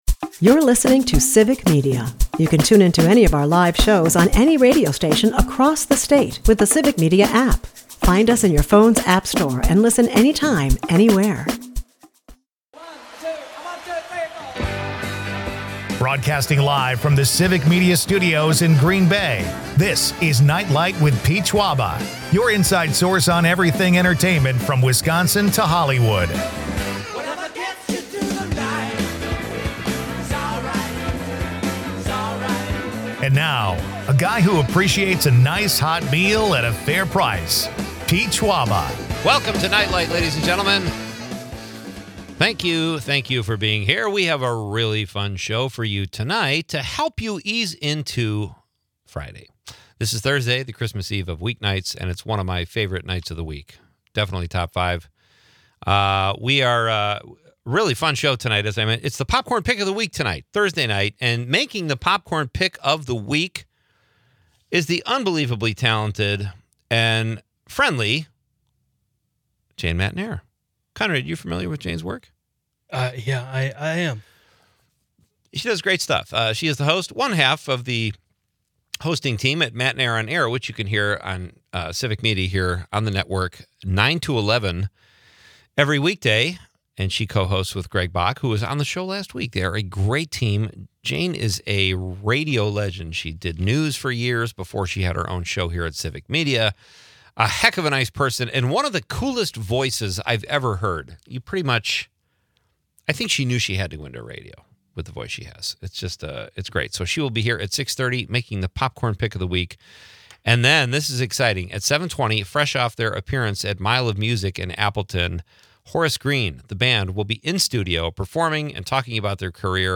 The show also dives into the nostalgia of childhood adventures reminiscent of 'The Goonies' and the fun of imagining life within our favorite TV or movie settings. The episode wraps with a laugh, sharing a humorous spoof of firefighter dramas, 'Wabeno Volunteer Fire.'